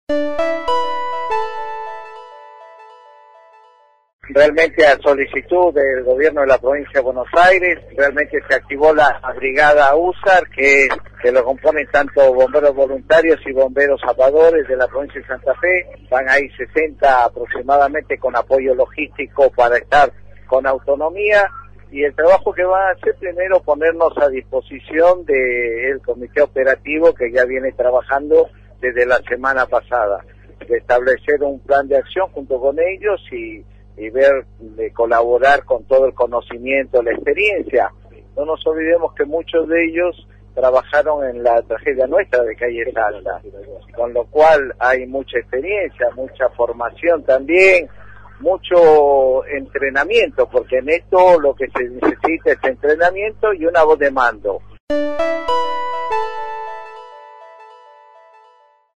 Escrito por LT3 Así lo confirmó en contacto con el móvil de LT3 el secretario de Protección Civil de la provincia, Marcos Escajadillo.